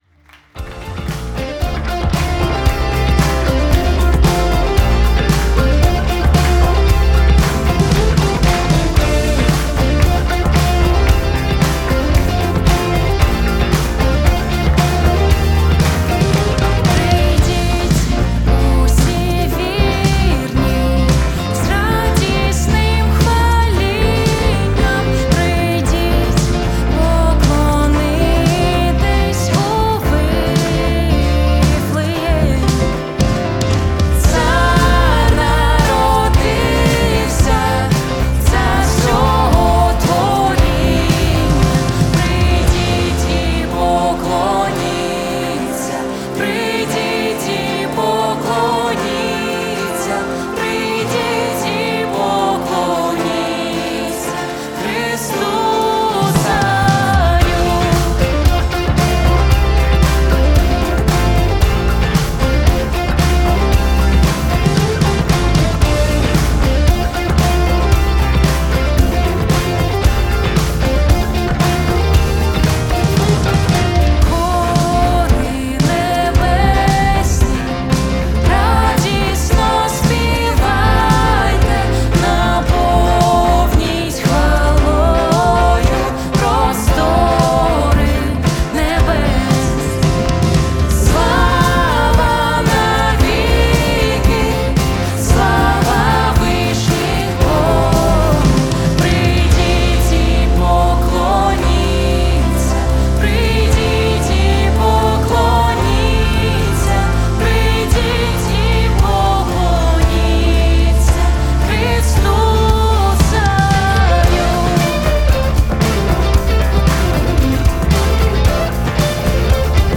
песня
523 просмотра 2 прослушивания 0 скачиваний BPM: 114